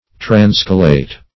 Search Result for " transcolate" : The Collaborative International Dictionary of English v.0.48: Transcolate \Trans"co*late\, v. t. [imp.